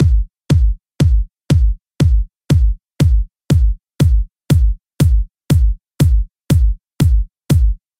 In the next audio example you can hear me fading up the hi hat slowly until it reaches a volume I am comfortable with. As you can hear, it’s just adding a very subtle amount of top end.